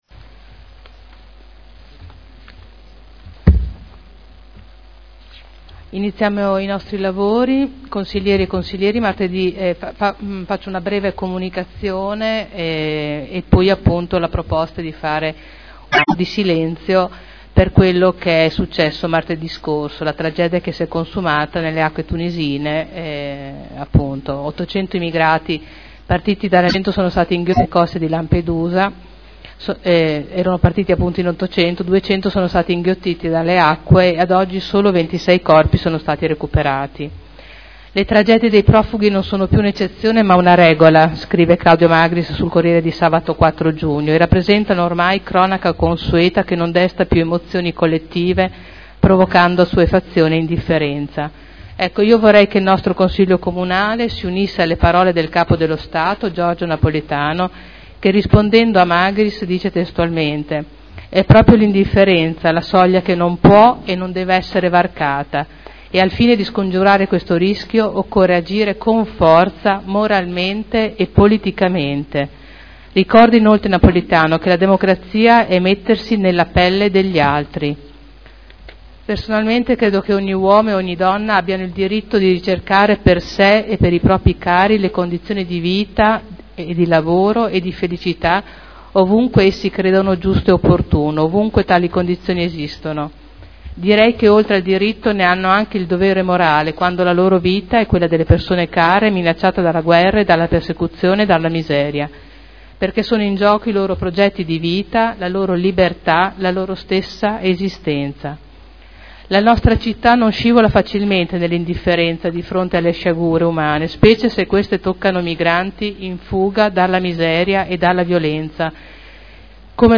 Seduta del 06/06/2011. Minuto di silenzio in segno di cordoglio verso gli immigrati naufragati nelle acque tunisine